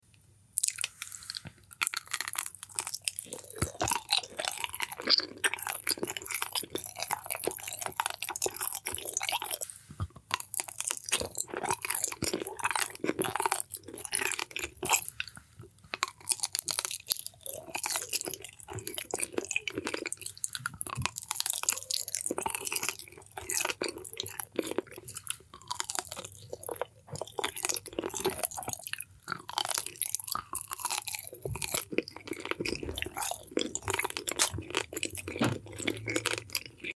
ASMR Satisfying Eating! Do You Sound Effects Free Download